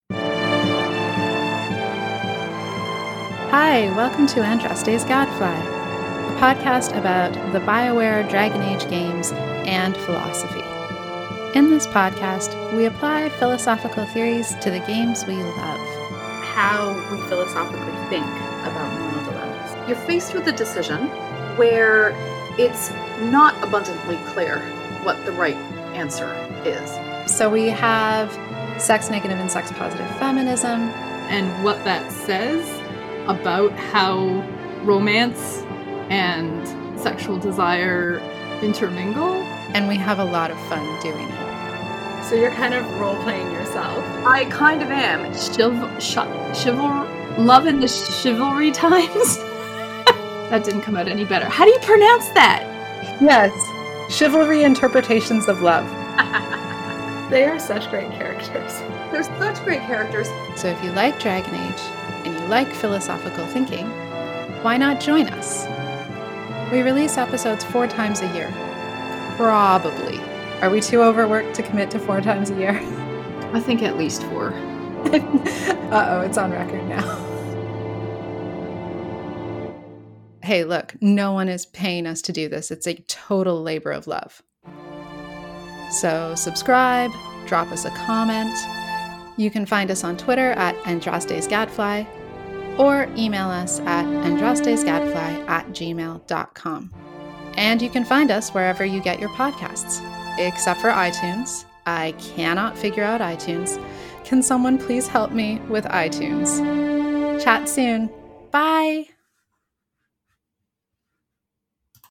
Here’s a bit of an introduction to the podcast of two philosophers who are slightly obsessed with the Dragon Age games!
1. Love the intro music, love the framing, really enjoy this as a trailer/teaser — would subscribe, even though philosophy spooks me.